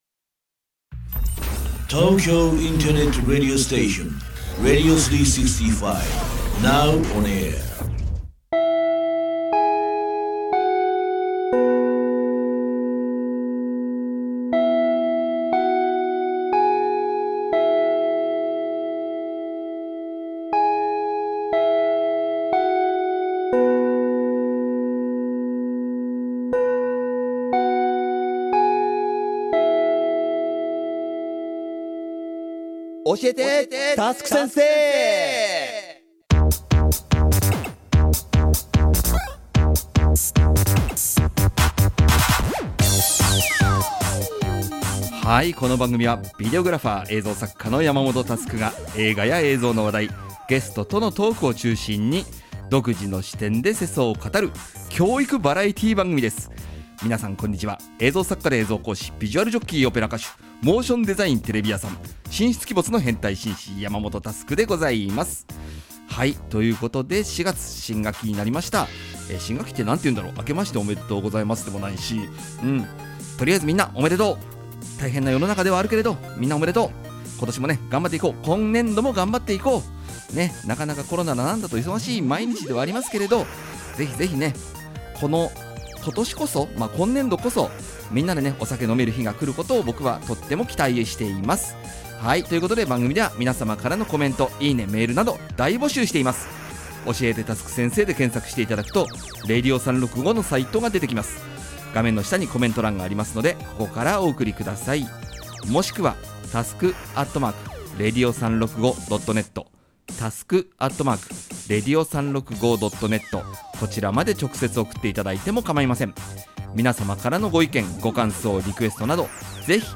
コーナー1：クリエイターズトーク